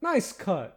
Add voiced sfx
nicecut4.ogg